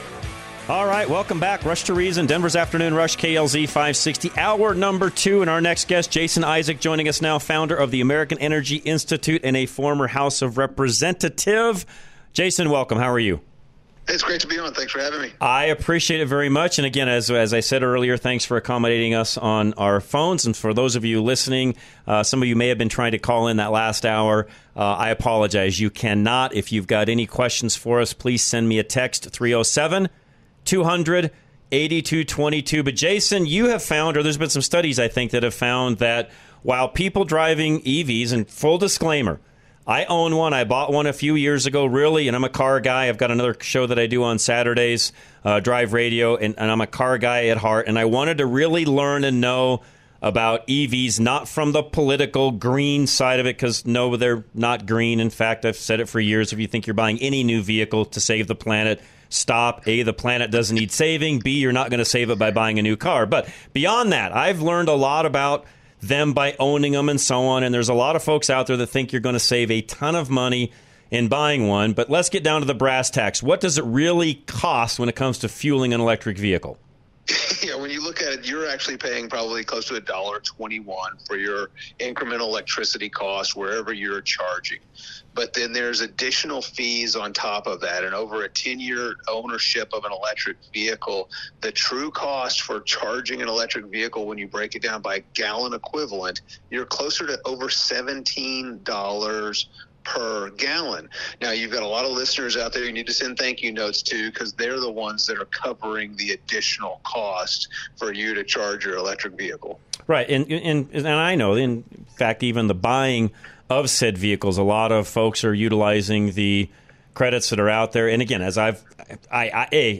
Rush To Reason - Interviews